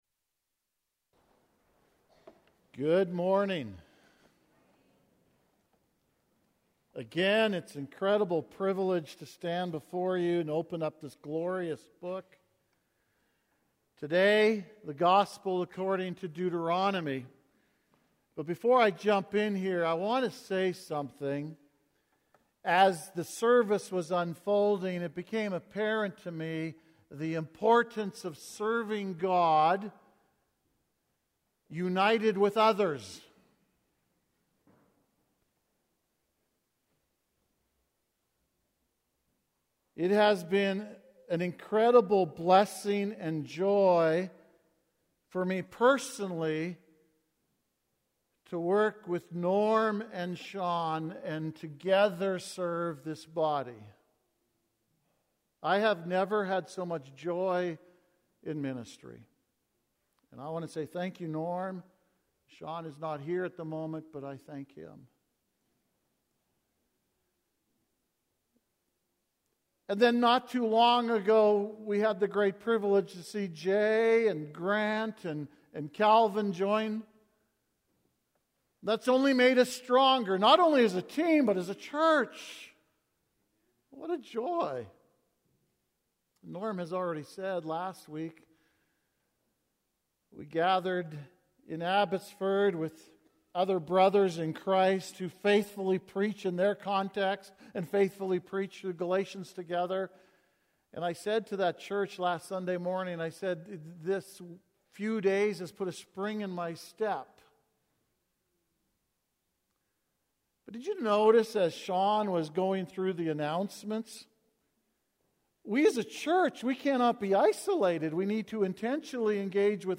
Sermons | First Baptist Church